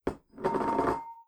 Impacts
clamour6.wav